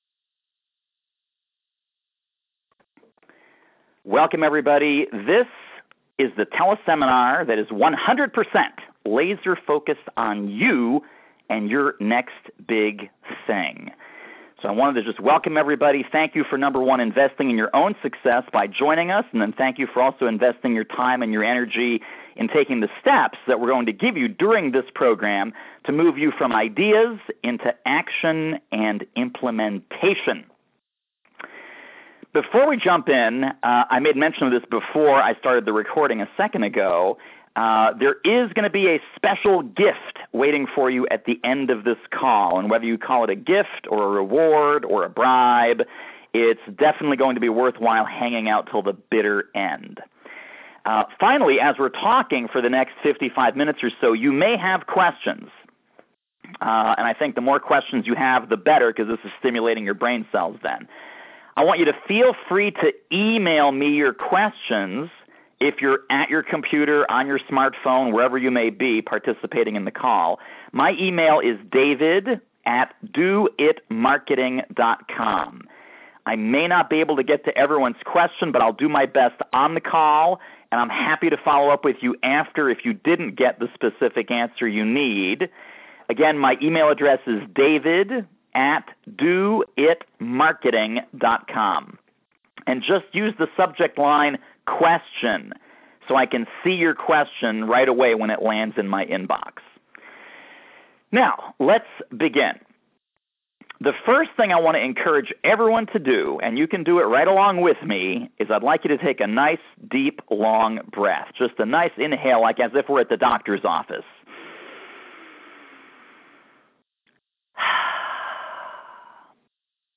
FREE Teleseminar